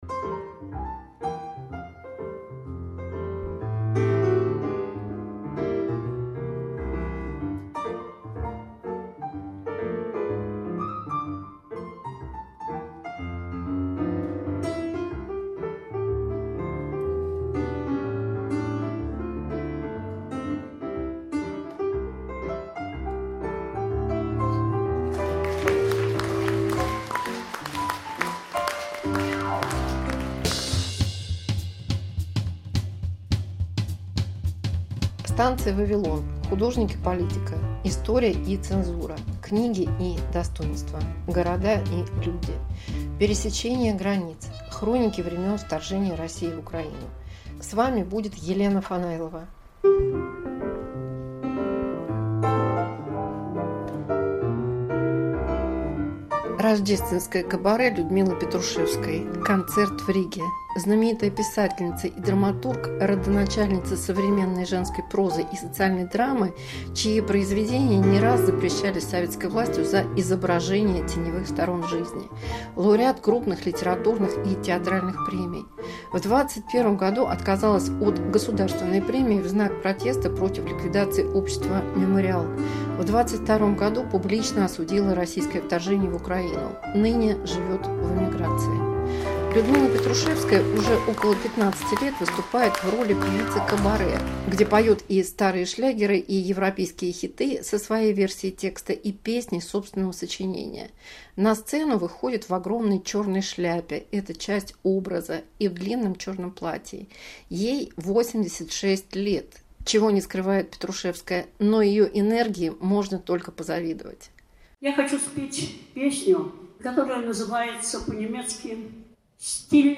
Писательница поёт